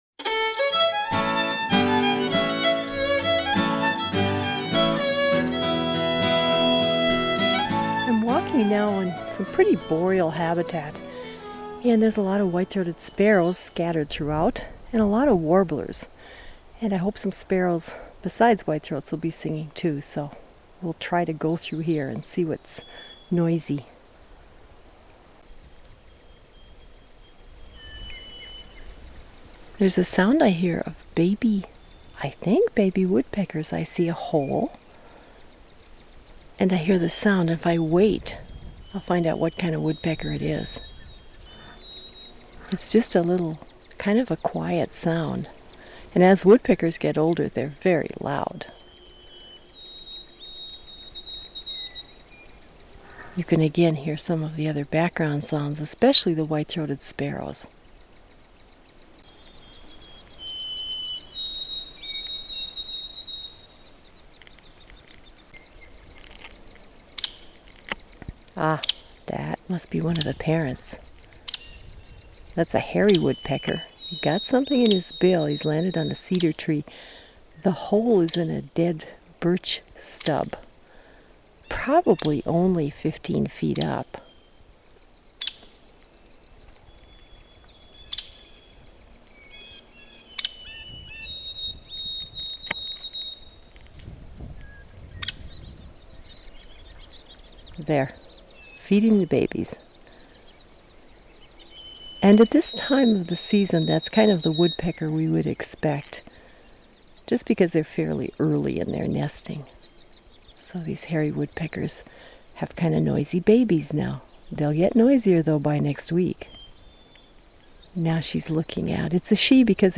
Recorded in the fields and woodlands of Northeastern Minnesota